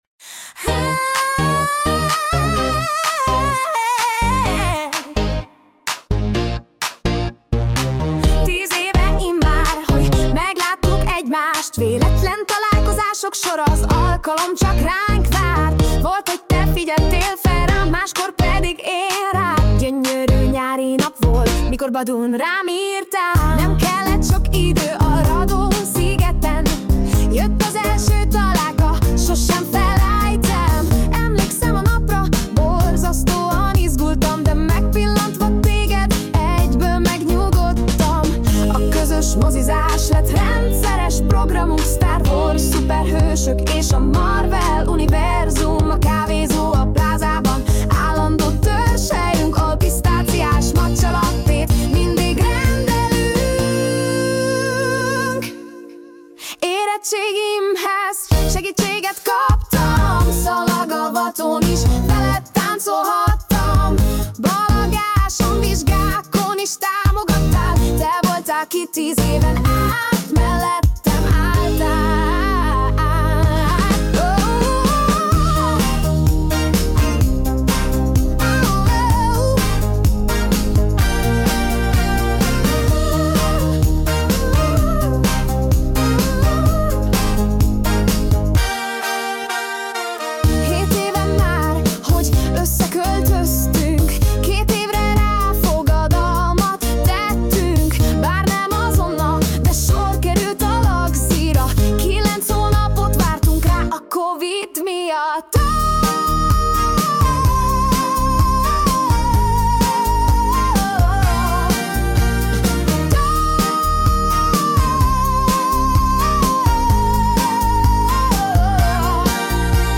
Light Pop - Évfordulóra